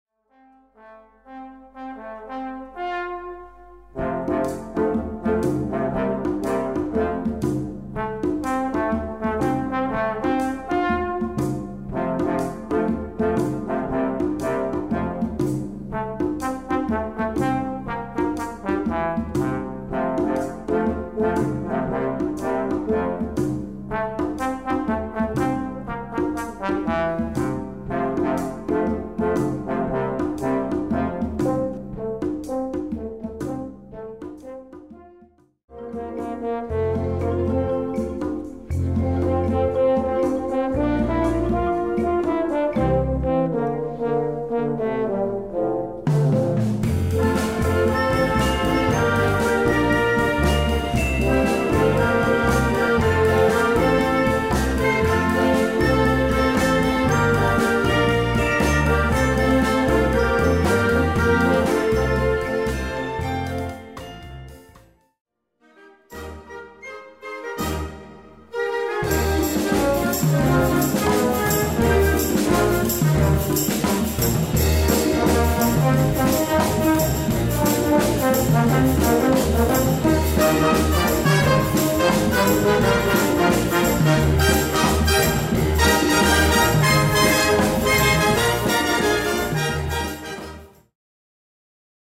5:30 Minuten Besetzung: Blasorchester Zu hören auf